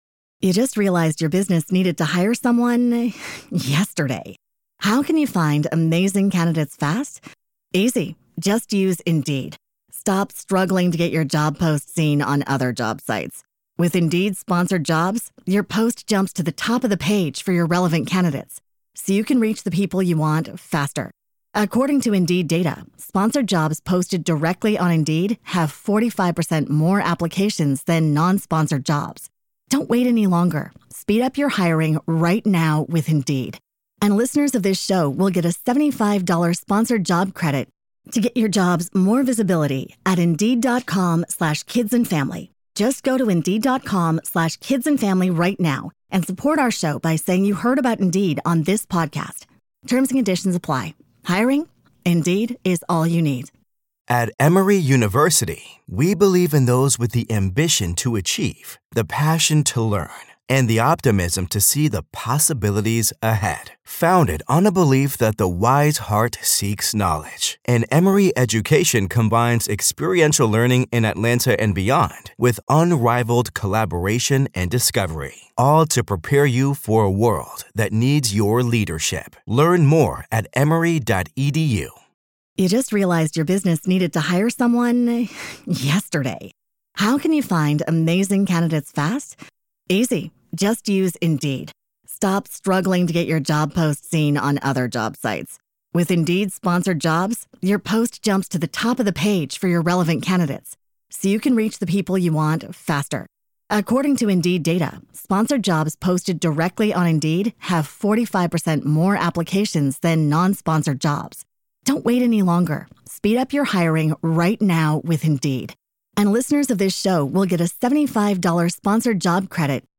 Welcome back to the Team Never Quit Podcast with your host, Marcus Luttrell.